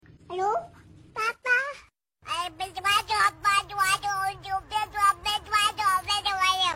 Baby Funny Sound Effects Free Download